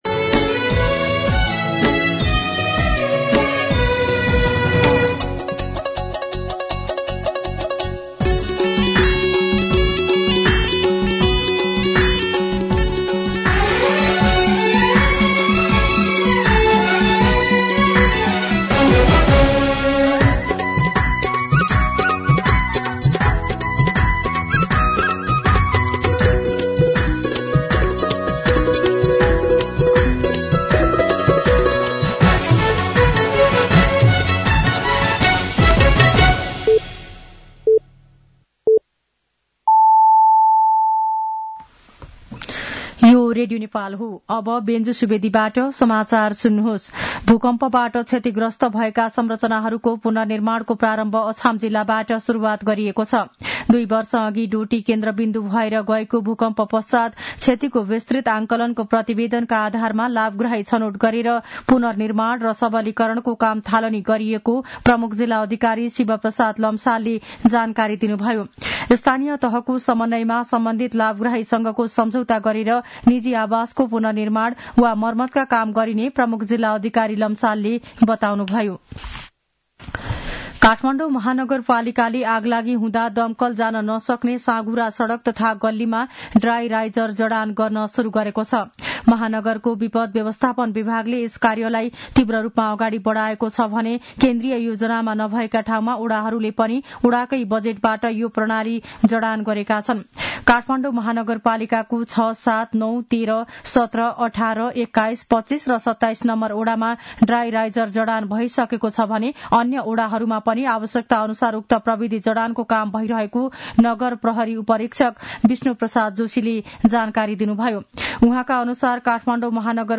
दिउँसो १ बजेको नेपाली समाचार : ६ माघ , २०८१
1-pm-Nepali-News-1.mp3